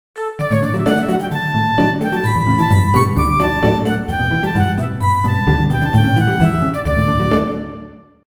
Tonalidad de Re mayor. Ejemplo.
desenfadado
festivo
jovial
melodía
sintetizador